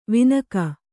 ♪ vinaka